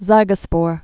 (zīgə-spôr, zĭgə-)